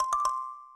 kalimba_c1d1c1.ogg